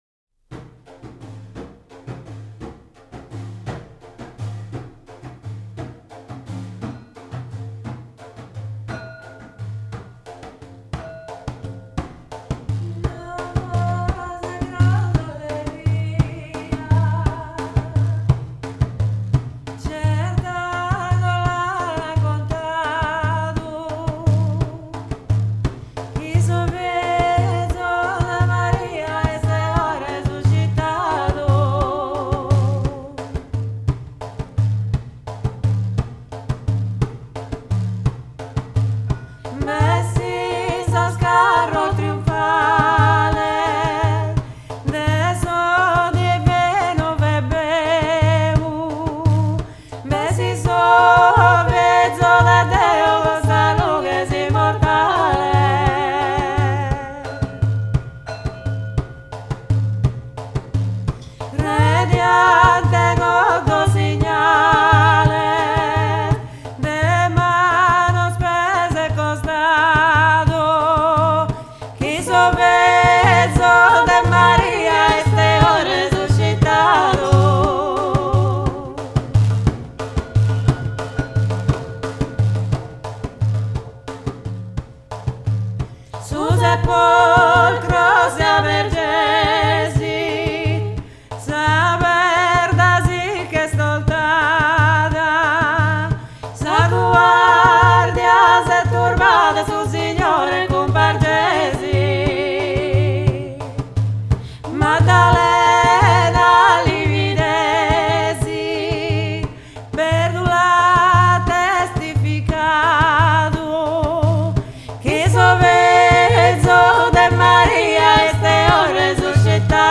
bendir
bells